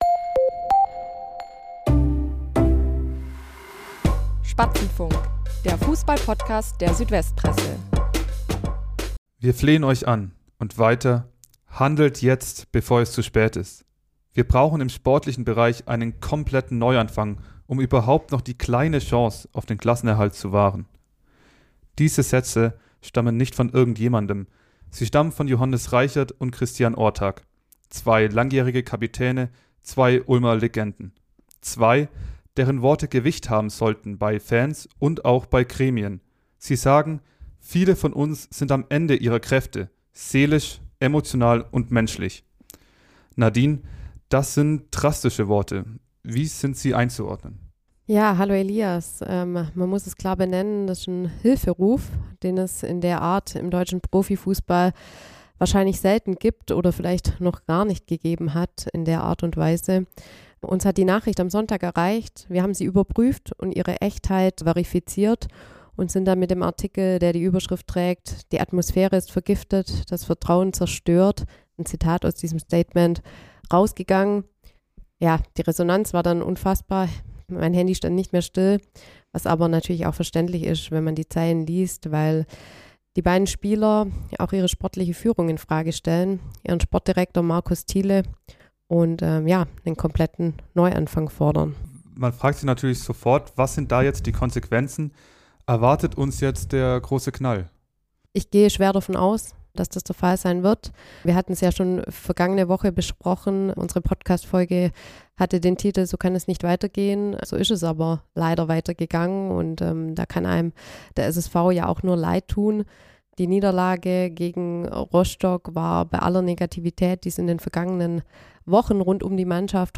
Was muss jetzt passieren? Darüber sprechen die Sport-Reporter